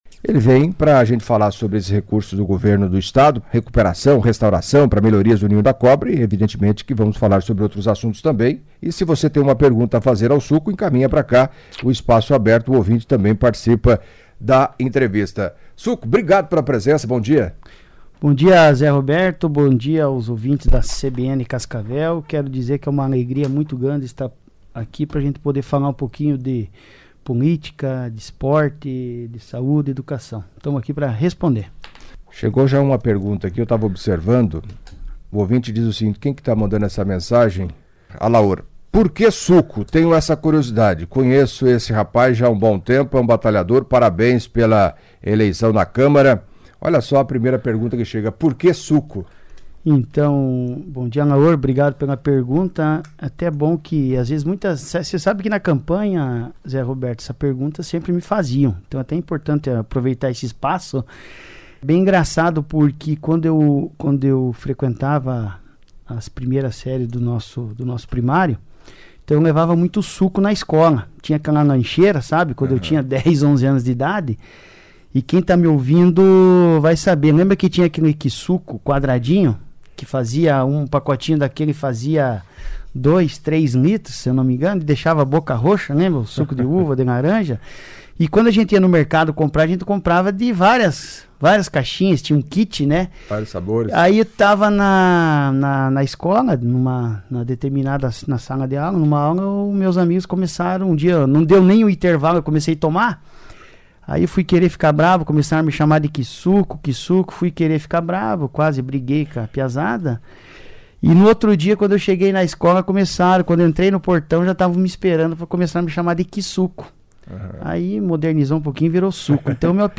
Em entrevista à CBN Cascavel nesta quinta-feira (28) Alexandre Guerino, popular Suco, vereador eleito do PSD com 1962 votos, inicialmente destacou a liberação de recursos por parte do governo do estado para revitalização do estádio Ninho da Cobra.